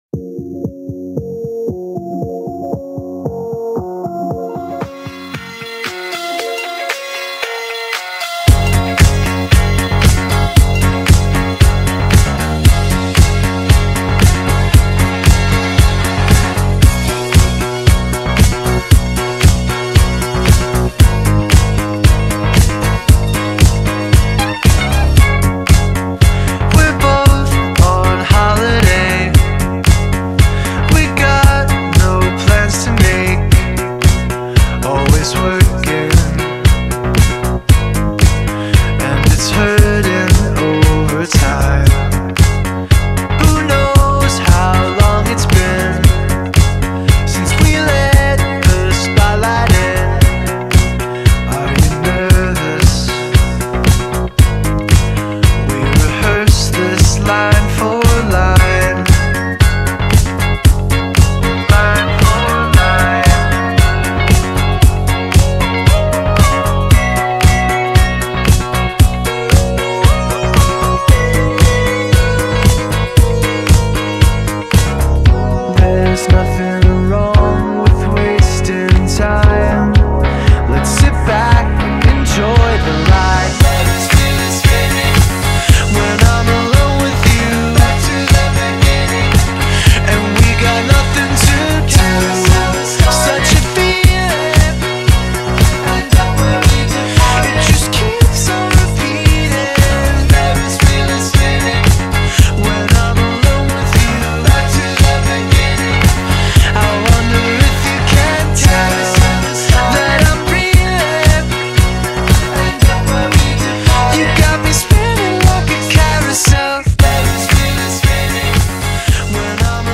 BPM115
Audio QualityPerfect (High Quality)
Comments[INDIE FUNK]